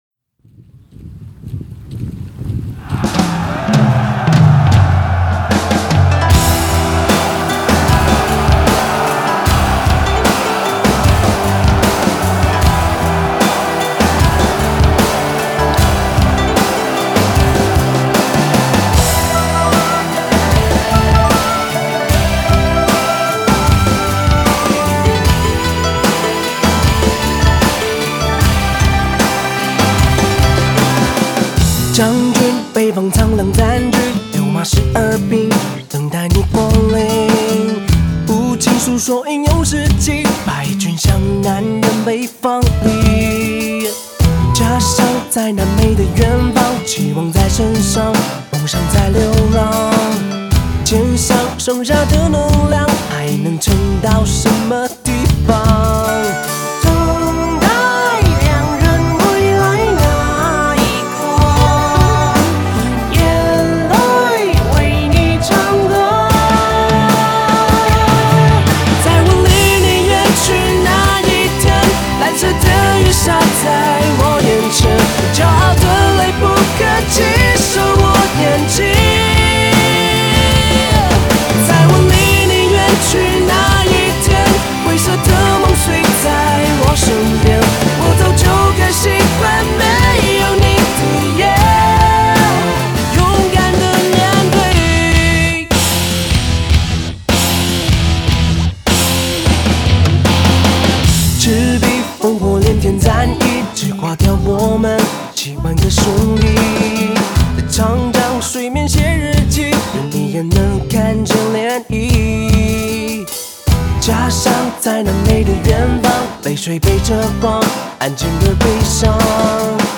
架子鼓